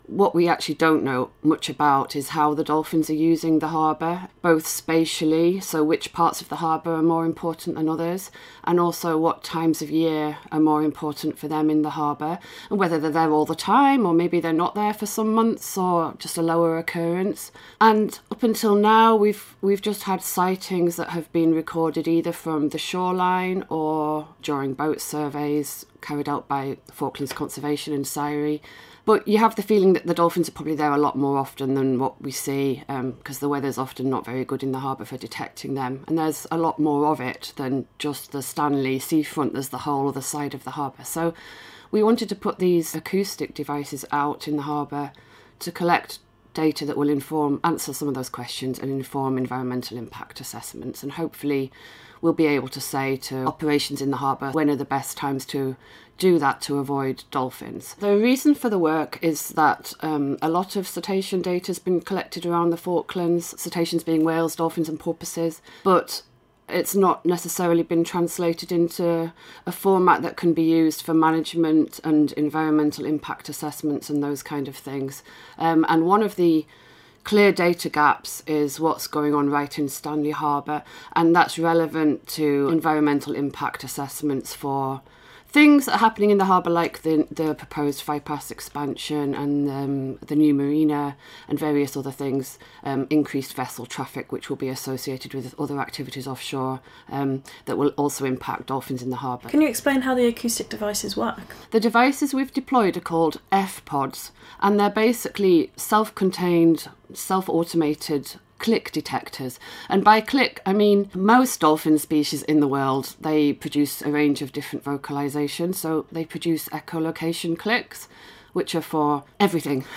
Falkland Islands Radio Station